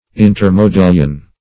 Search Result for " intermodillion" : The Collaborative International Dictionary of English v.0.48: Intermodillion \In`ter*mo*dil"lion\, n. (Arch.) The space between two modillions.